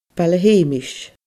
Baile Sheumais /balə heːmɪʃ/